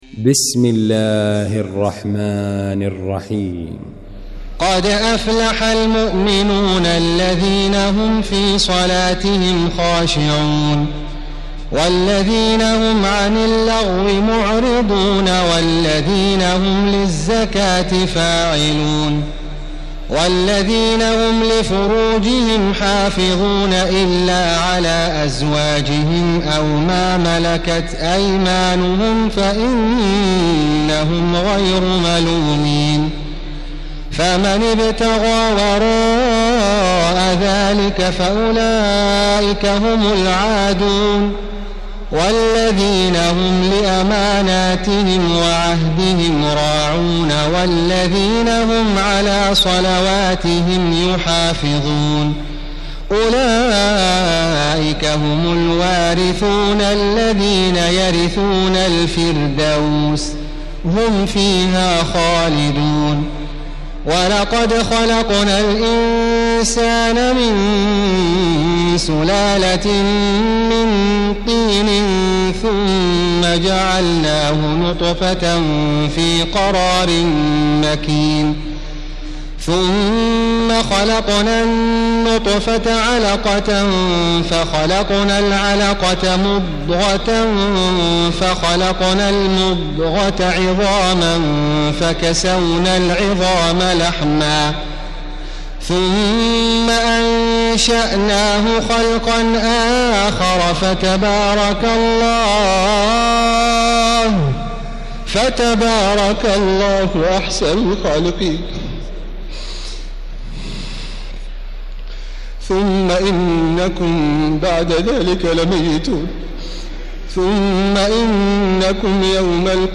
المكان: المسجد الحرام الشيخ: خالد الغامدي خالد الغامدي المؤمنون